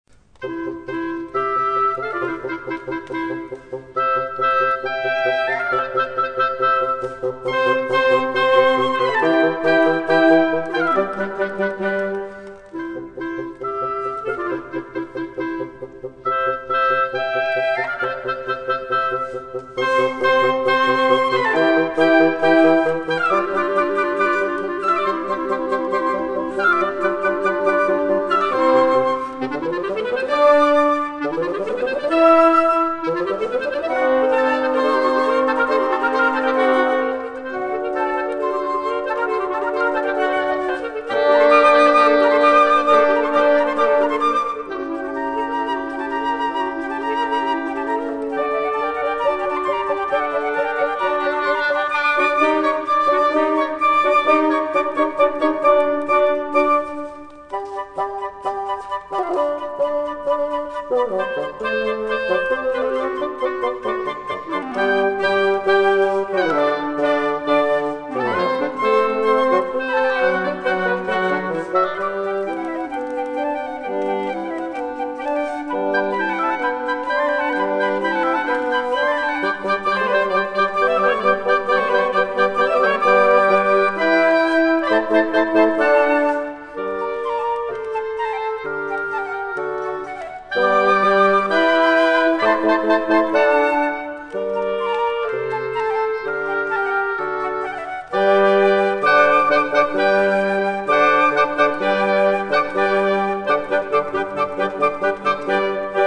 Registrazione live effettuata il 3 Gennaio 2008
nella Chiesa Regina Coeli – AIROLA (BN)